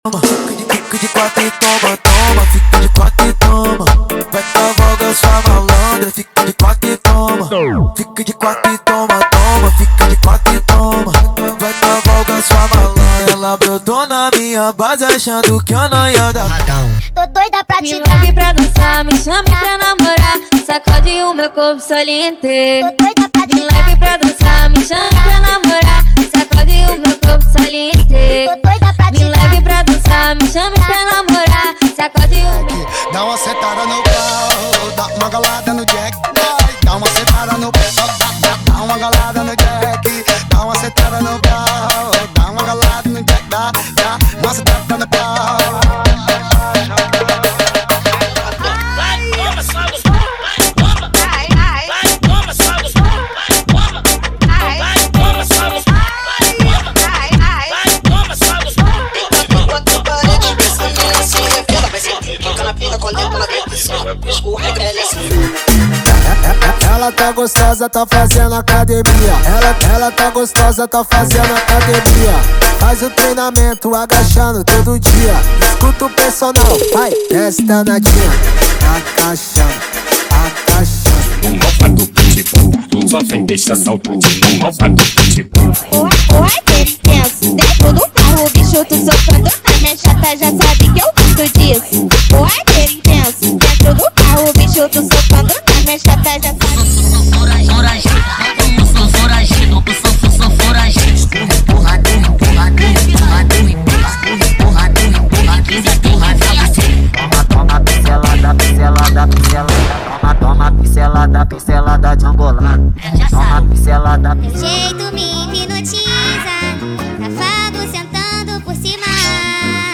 Os Melhores Brega Funk do momento estão aqui!!!
• Sem Vinhetas